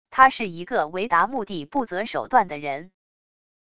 他是一个为达目的不择手段的人wav音频生成系统WAV Audio Player